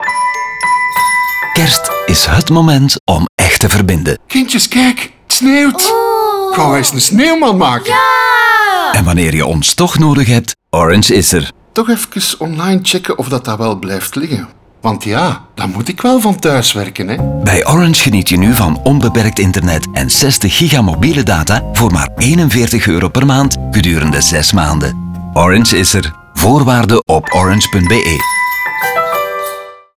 Dit alles wordt ondersteund door een warme cover